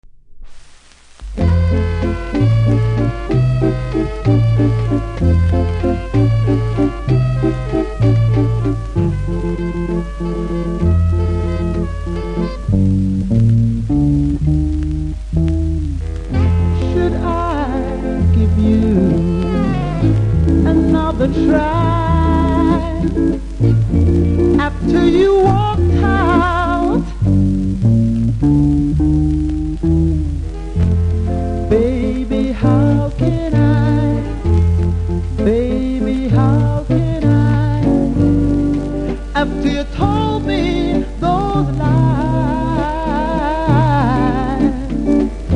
バラードなのでプレスノイズ目立ちます。
黒ペンで書き込み、両面プレスノイズあります。